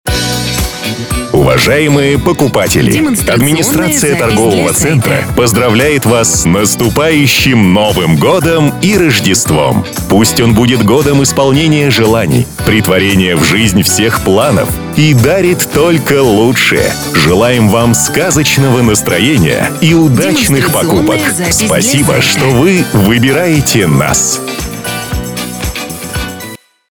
023_Поздравление С Новым Годом_мужской.mp3